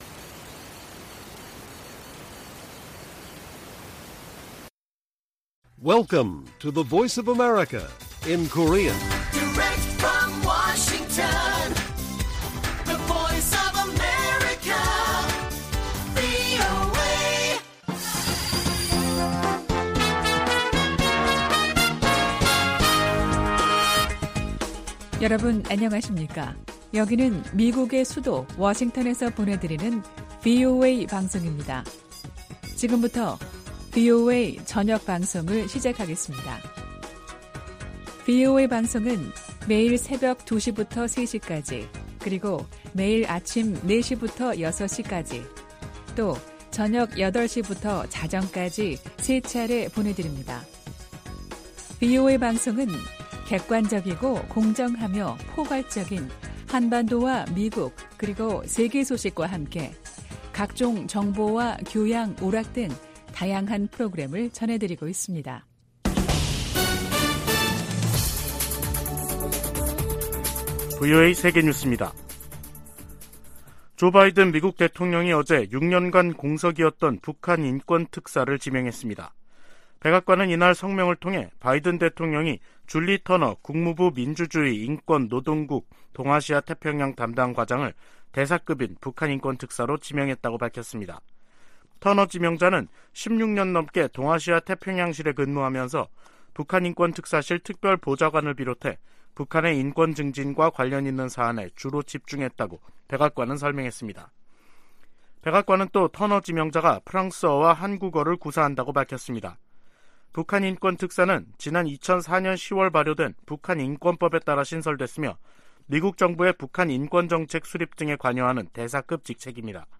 VOA 한국어 간판 뉴스 프로그램 '뉴스 투데이', 2023년 1월 24일 1부 방송입니다. 국무부는 북한과 러시아 용병 회사 간 무기거래와 관련해 한국 정부와 논의했다고 밝혔습니다. 북한에서 열병식 준비 정황이 계속 포착되는 가운데 평양 김일성 광장에도 대규모 인파가 집결했습니다.